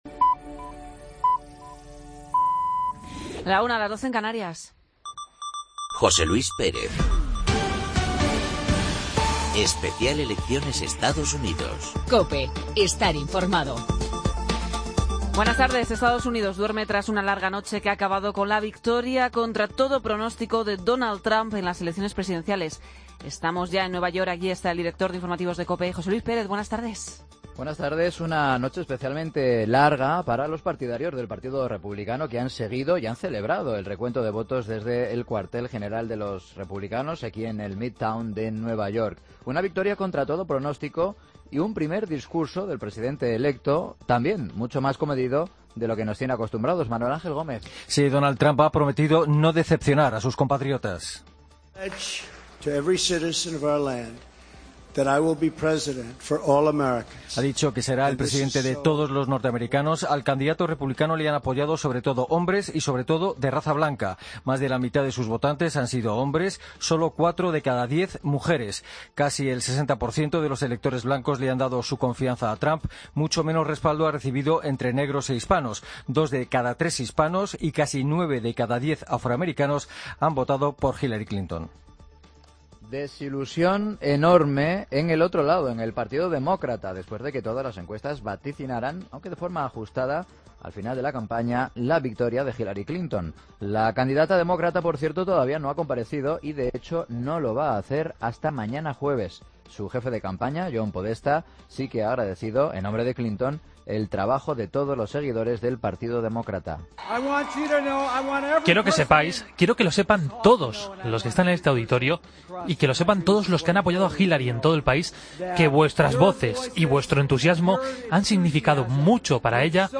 El boletín de noticias de las 13 horas del 9 de noviembre de 2016